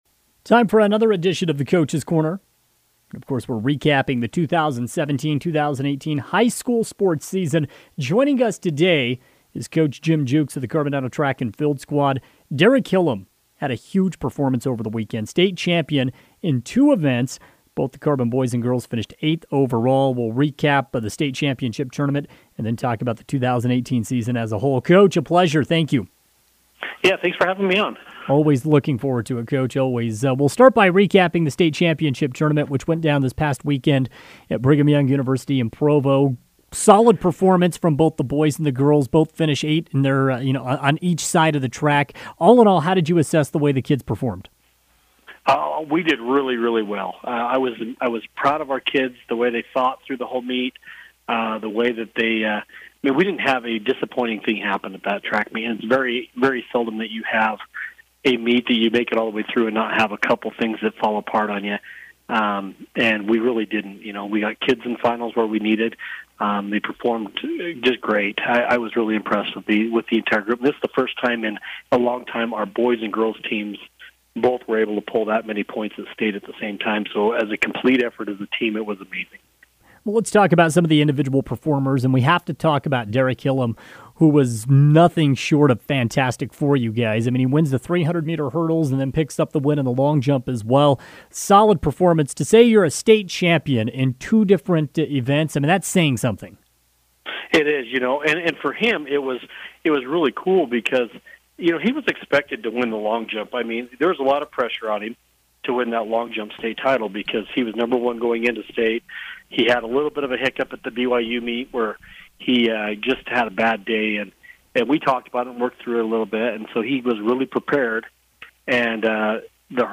was a guest on KOAL's Drive Time Sports Tuesday afternoon to recap the 2018 campaign.